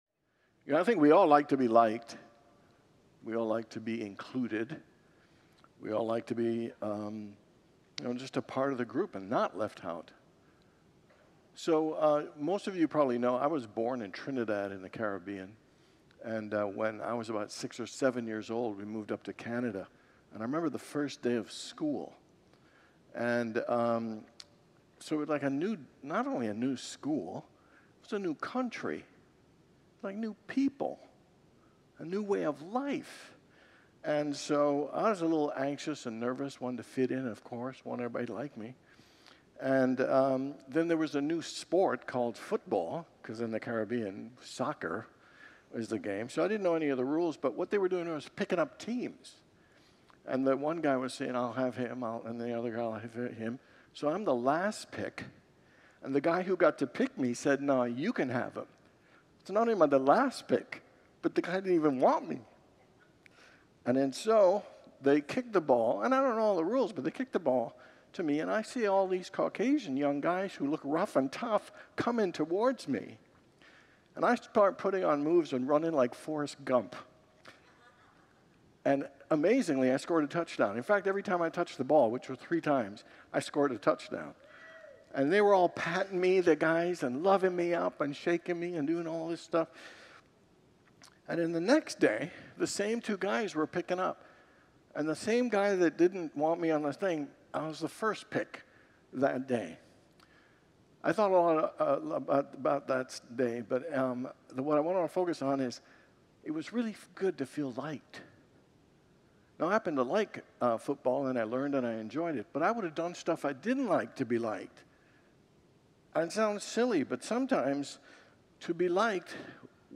Series: Wednesday Evening Worship